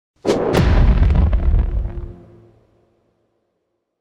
divine-smite-target-01.ogg